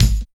103 KICK 3.wav